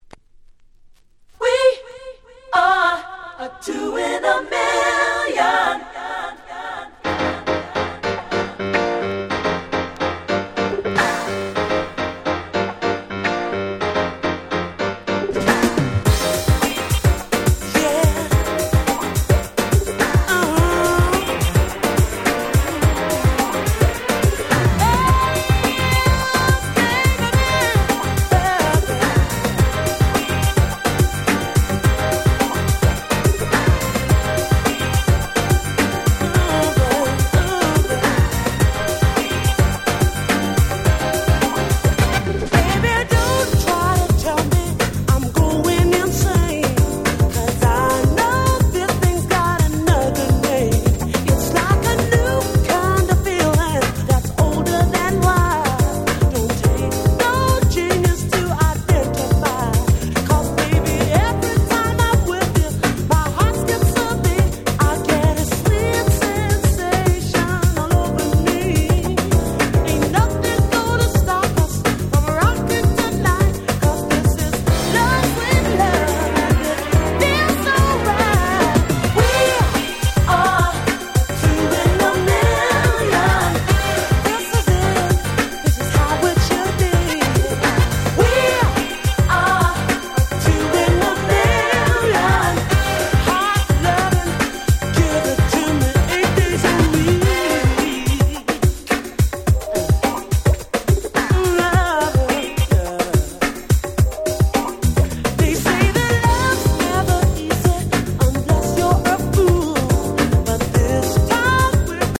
90's UK Soul UK R&B